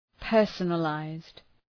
Shkrimi fonetik{‘pɜ:rsənə,laızd}
personalized.mp3